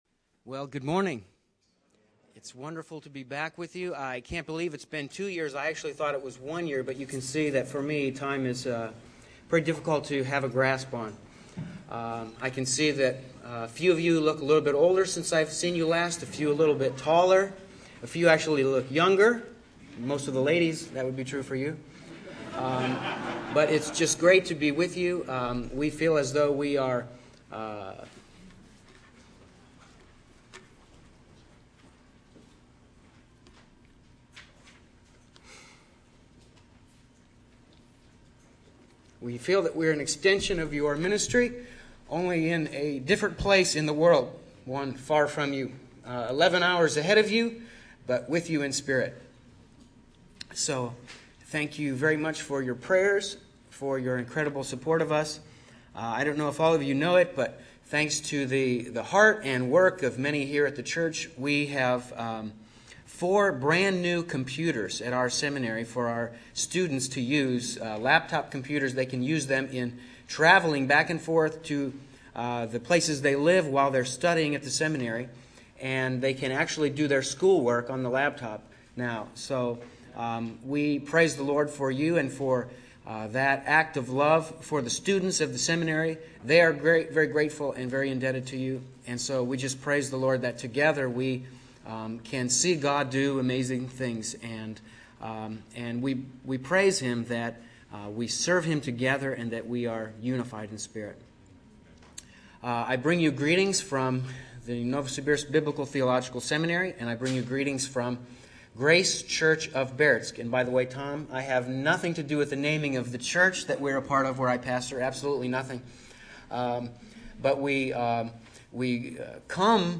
Category: Sunday Service